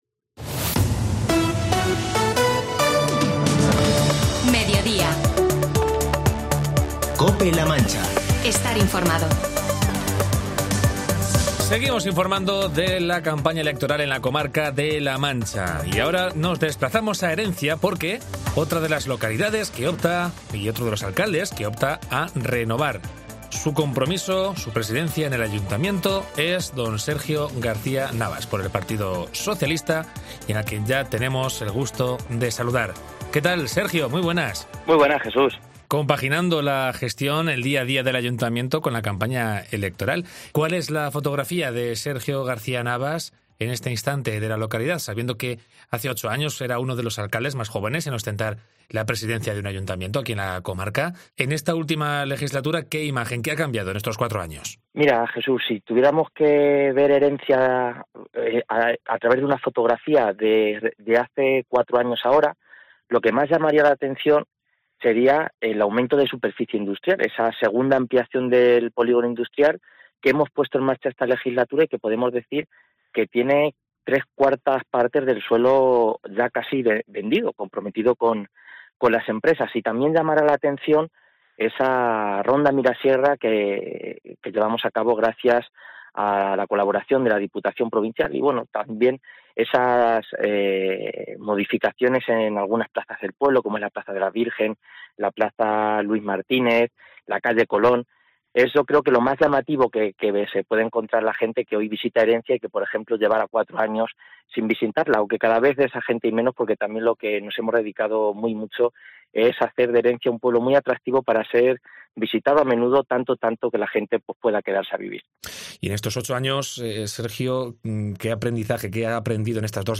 Entrevista a Sergio García-Navas, candidato del PSOE a la alcaldía del Ayuntamiento de Herencia